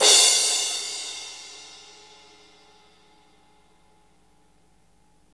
Index of /90_sSampleCDs/Roland L-CD701/CYM_Crashes 1/CYM_Crash menu
CYM CRASH03R.wav